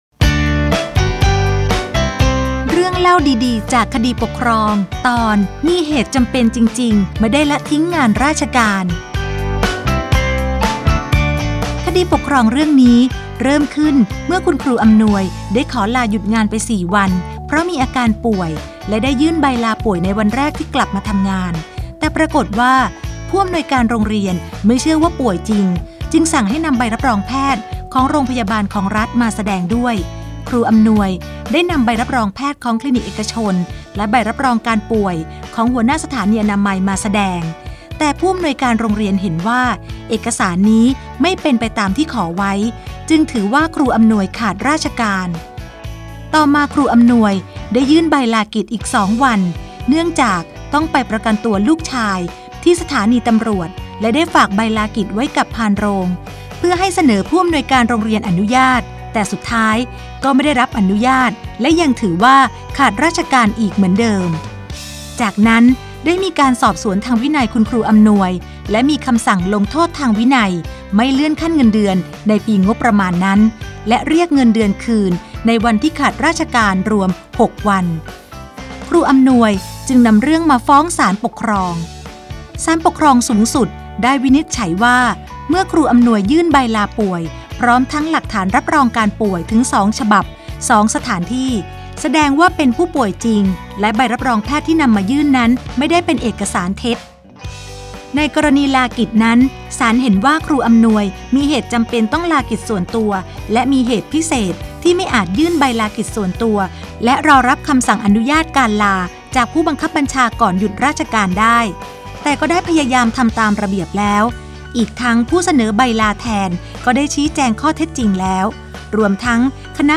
สารคดีวิทยุ เรื่องเล่าดีดี...จากคดีปกครอง - มีเหตุจำเป็นจริงๆ ไม่ได้ละทิ้งงานราชการ